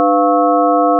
sin_5.1ch.wav